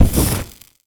fireball_impact_burn_03.wav